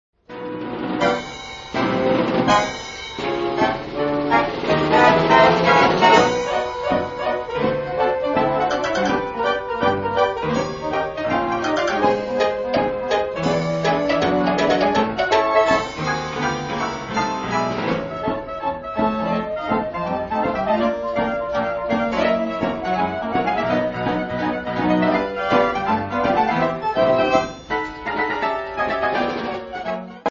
He also takes a number of piano breaks in this arrangement.